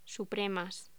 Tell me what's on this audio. Locución: Supremas